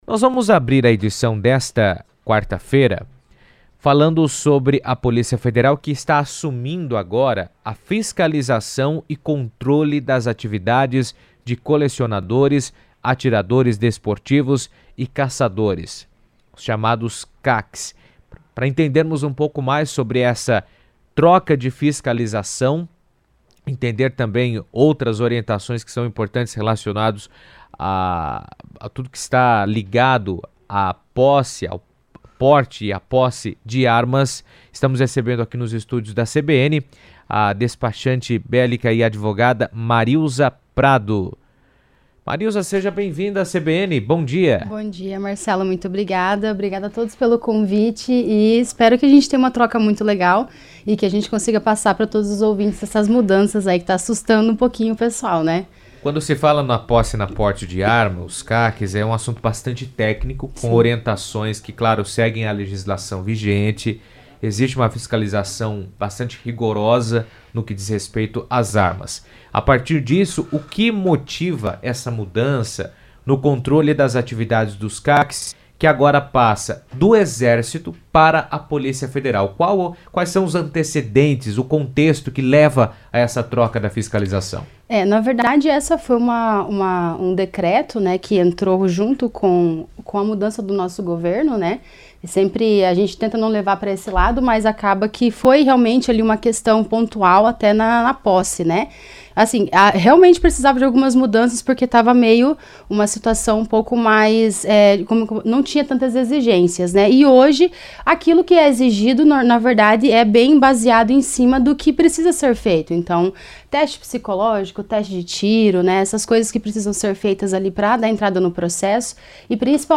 esteve na Rádio CBN e comentou os impactos da mudança.